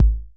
drum35.mp3